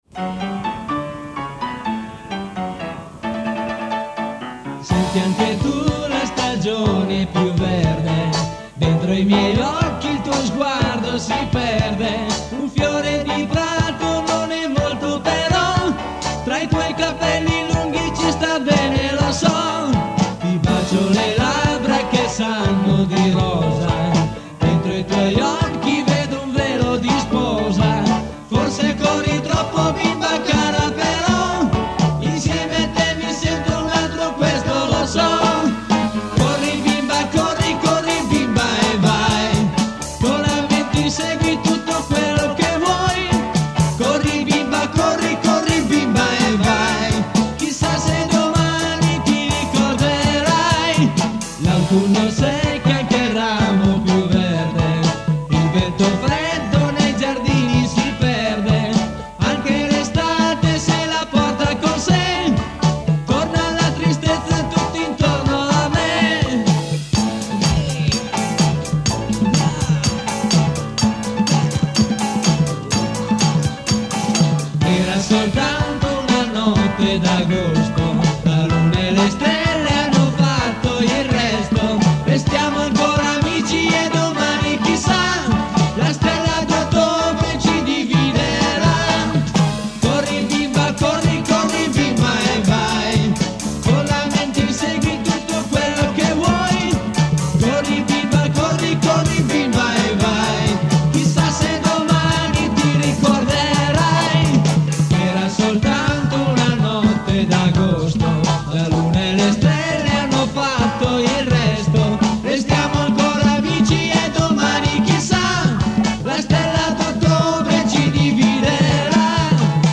(Provini realizzati in sala prove)